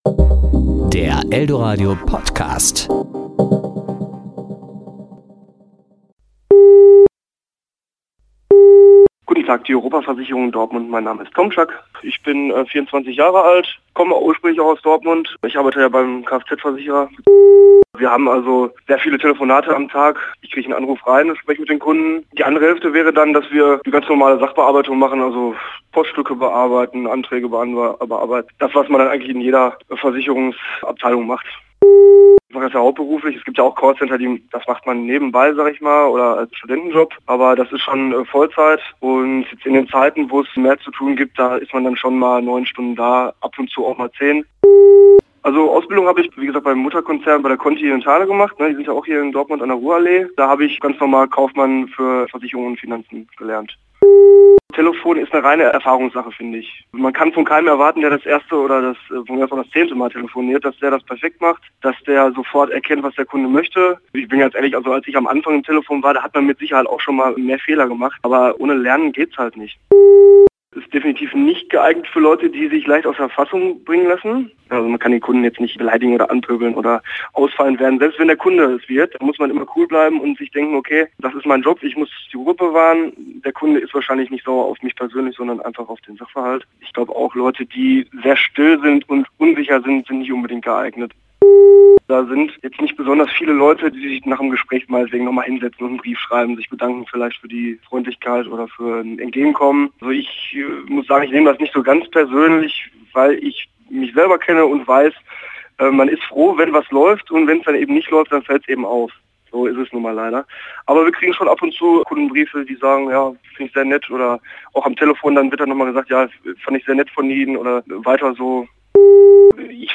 Natürlich stilecht am Telefon.